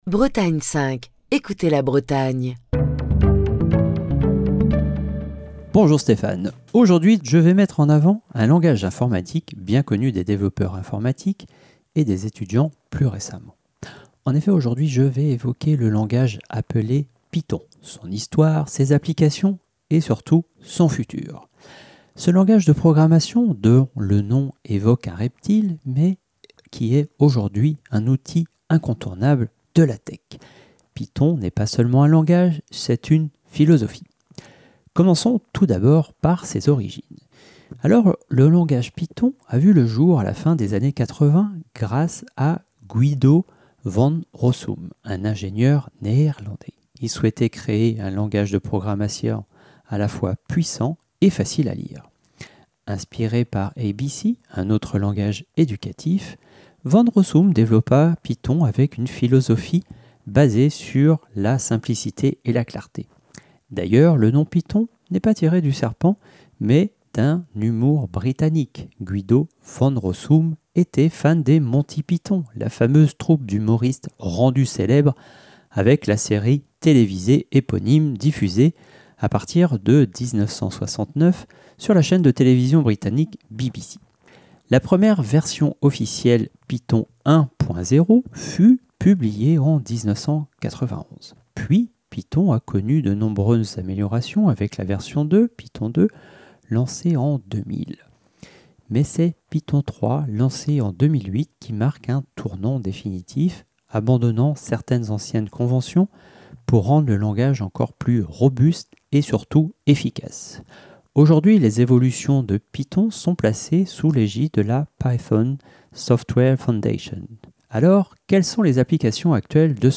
Chronique du 11 juin 2025.